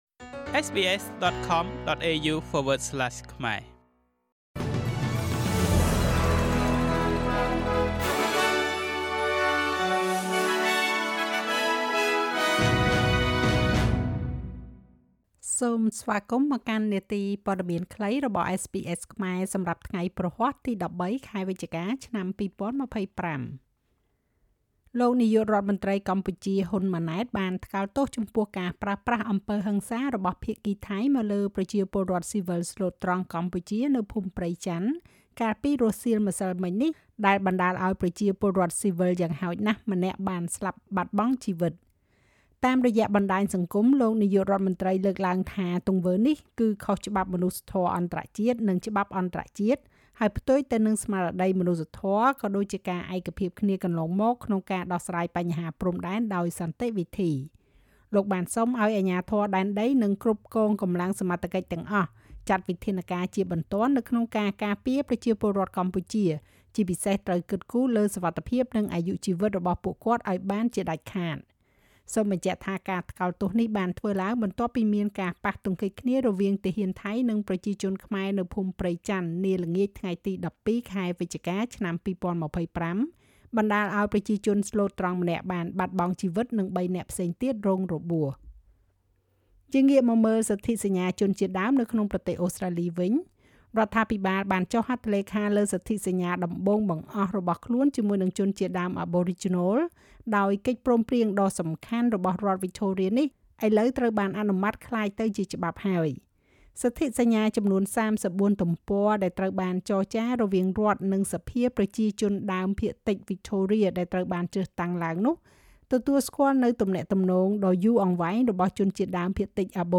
នាទីព័ត៌មានខ្លីរបស់SBSខ្មែរសម្រាប់ថ្ងៃព្រហស្បតិ៍ ទី១៣ ខែវិច្ឆិកា ឆ្នាំ២០២៥